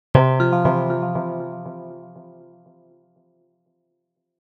Scifi 13.mp3